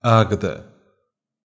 Águeda (pronounced [ˈaɣɨðɐ]
Pt-pt_Águeda_FF.ogg.mp3